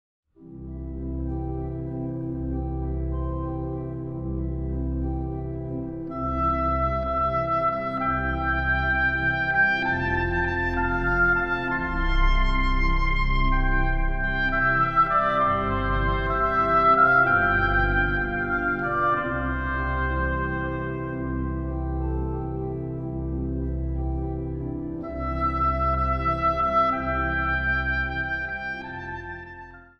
Instrumentaal | Hobo